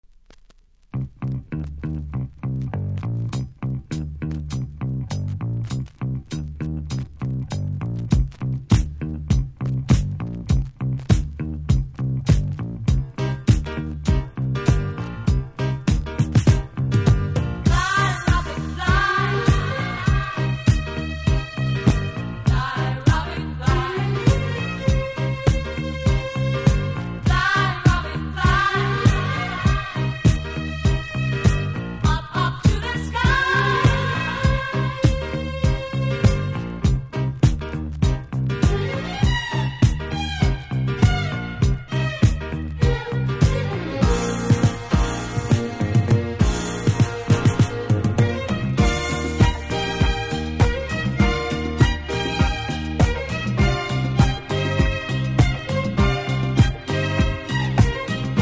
DISCO
一本派手なキズ、多少ノイズありますがプレイは問題ないレベルだと思いますので試聴で確認下さい。